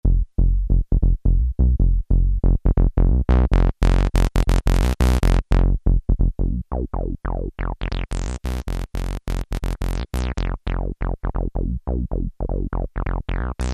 These are a few simple demo sounds I just came up with:
Classic squelchy bass line.
Tempo 140BPM (supposedly)   Osc1: 32' sync=on wave=saw
moogbas2.mp3